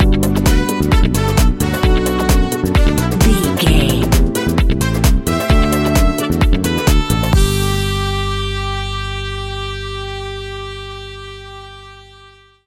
Ionian/Major
groovy
uplifting
energetic
bass guitar
brass
saxophone
drums
electric piano
electric guitar
deep house
nu disco
synth
upbeat